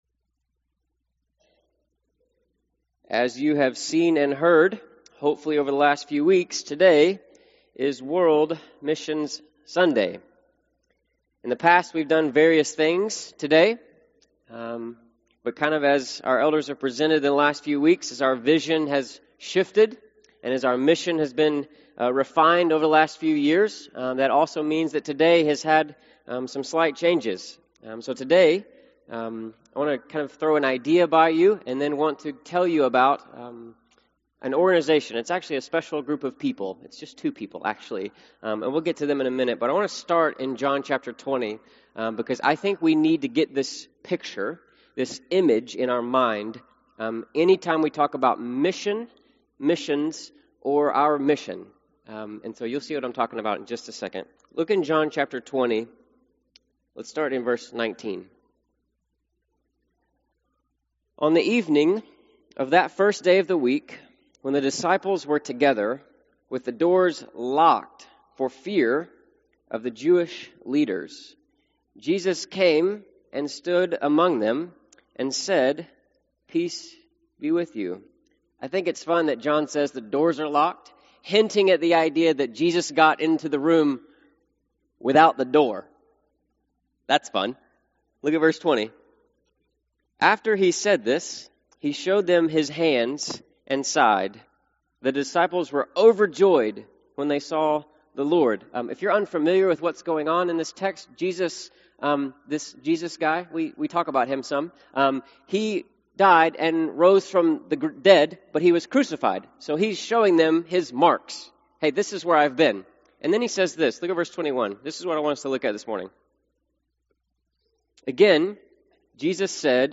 University Church of Christ Sermons
missions-sunday-2018.mp3